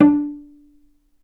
vc_pz-D4-ff.AIF